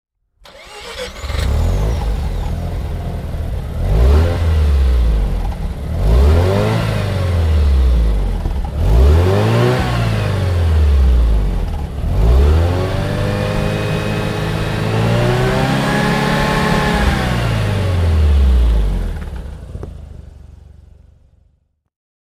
Mazda RX-7 Turbo II Convertible (1990) - Starten und Leerlauf
Mazda_RX7_Turbo_II_1990.mp3